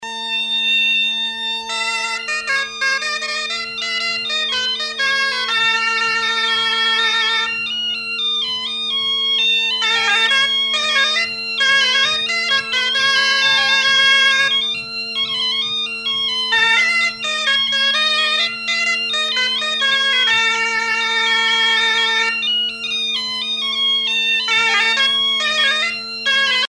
danse : riqueniée
Pièce musicale éditée